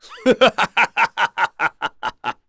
Chase-Vox_Happy3.wav